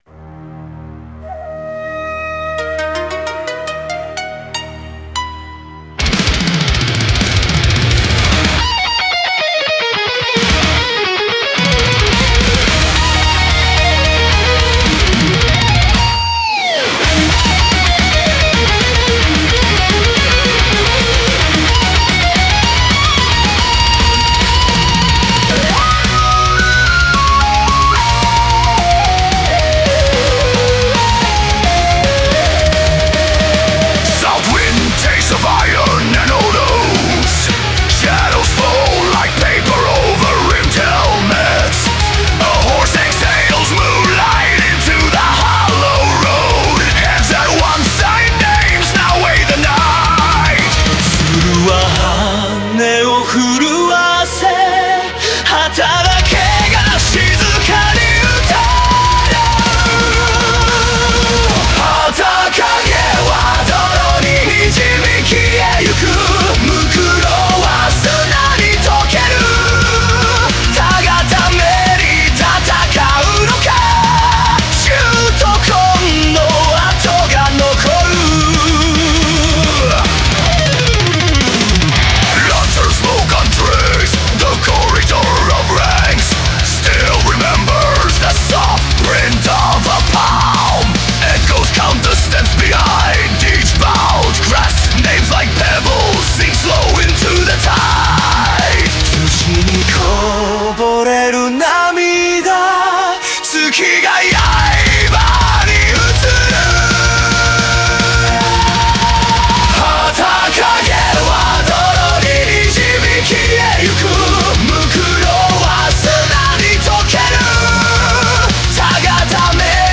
Melodic Death Metal